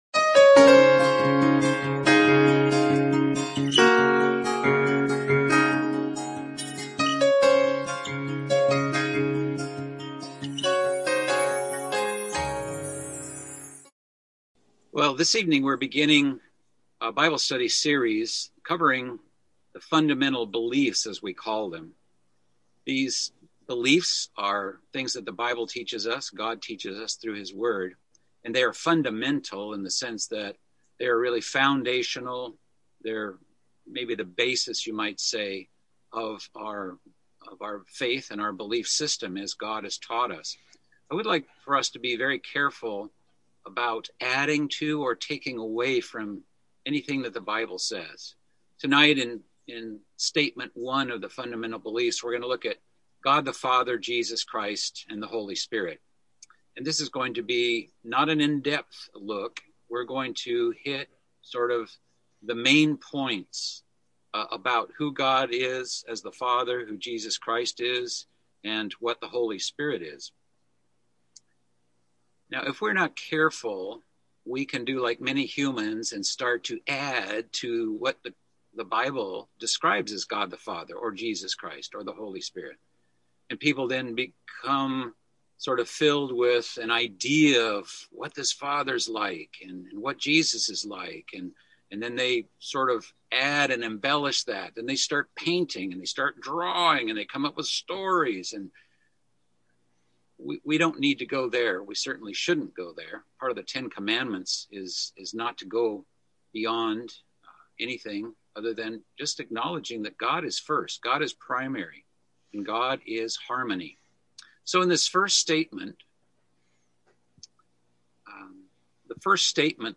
Bible Study - Fundamental Biblical Beliefs